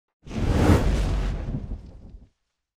flame_attack.wav